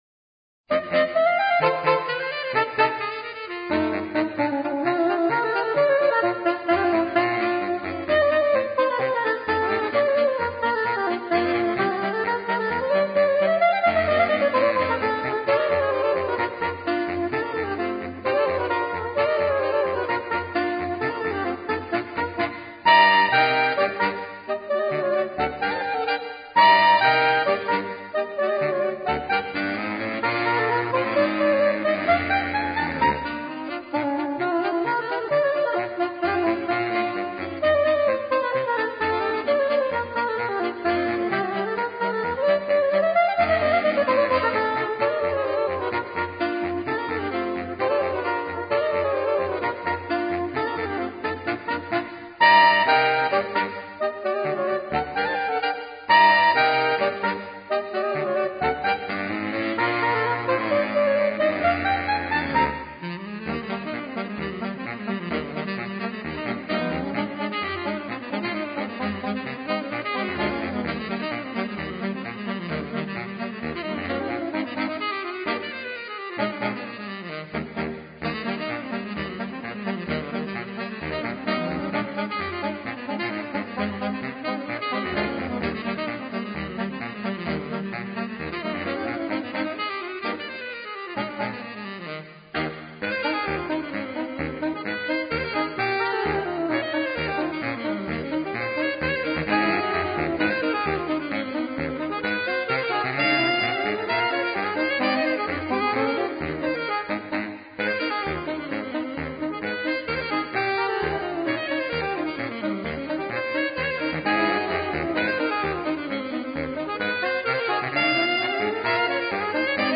Per quartetto di sax